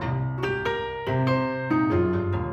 Index of /musicradar/gangster-sting-samples/95bpm Loops
GS_Piano_95-C1.wav